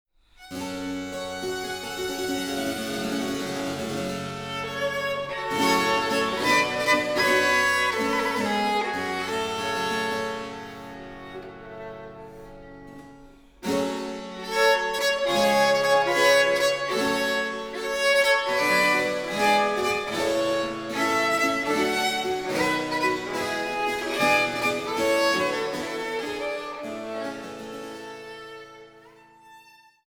Sonate Nr. 10 g-Moll „Die Kreuzigung“ aus den Rosenkranzsonaten für Violine und B.c. (1674)